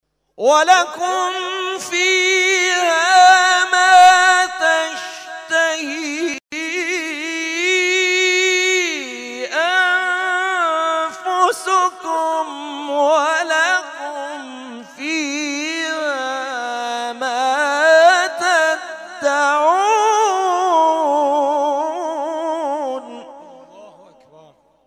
محفل انس با قرآن در آستان عبدالعظیم(ع)
قطعات تلاوت